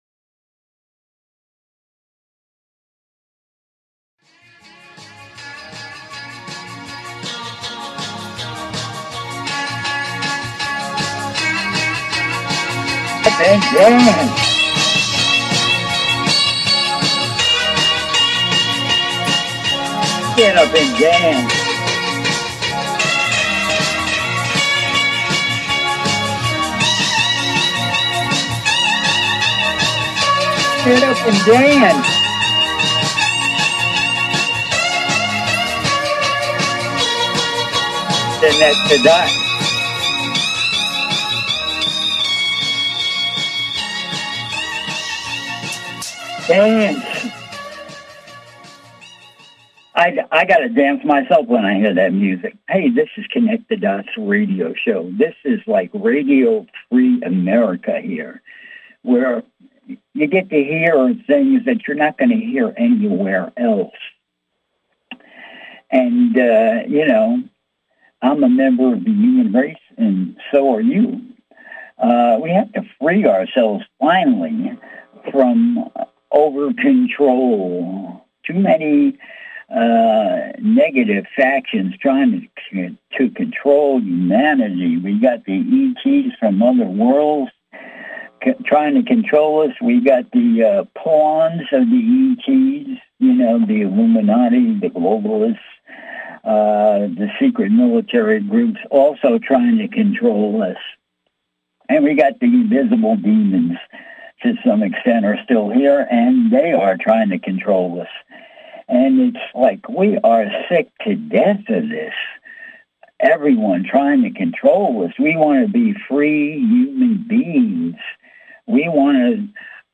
Talk Show Episode, Audio Podcast
call in radio talk show